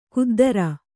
♪ kuddara